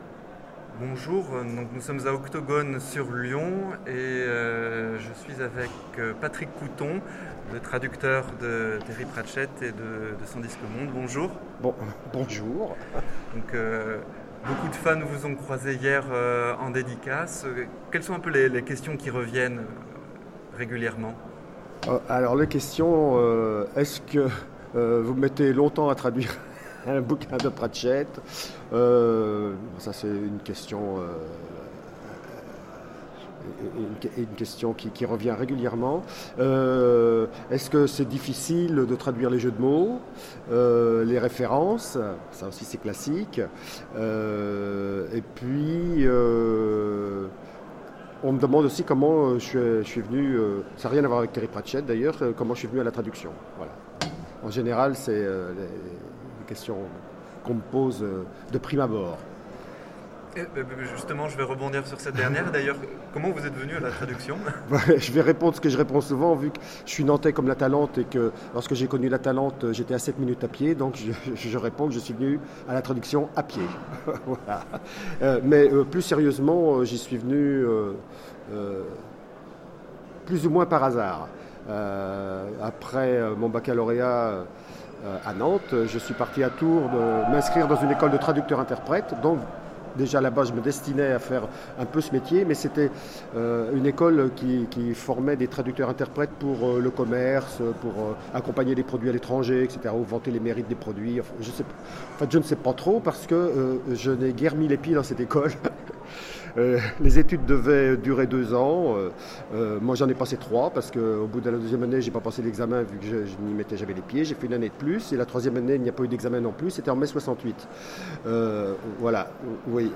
Octogones 2015
Conférence